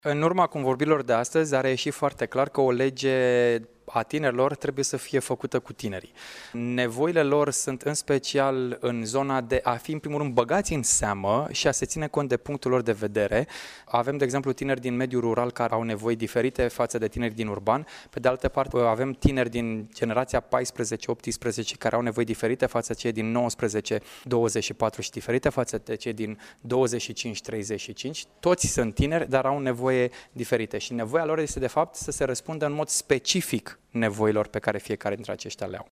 Legea tinerilor din România va suferi anumite modificări fundamentale a afirmat, astăzi, la Iaşi secretarul de stat din Ministerul Tineretului şi Sportului, Andrei Popescu.